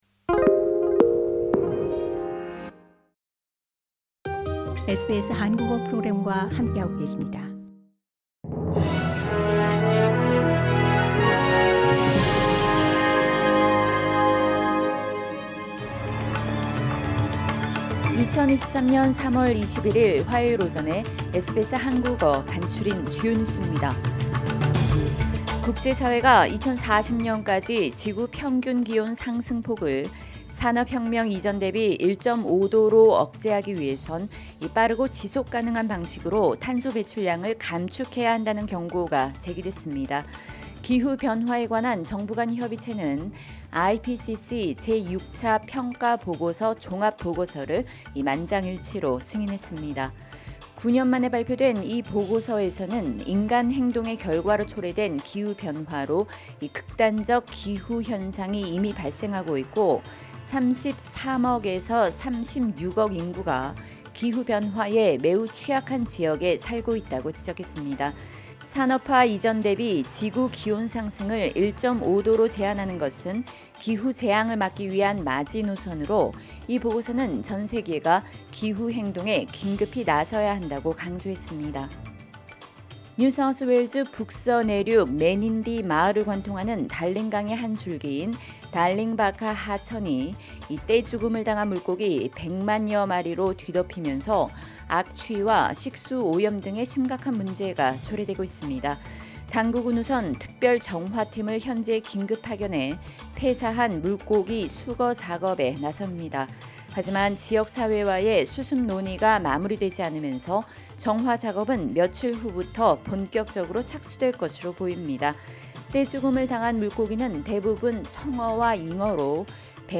SBS 한국어 아침 뉴스: 2023년 3월 21일 화요일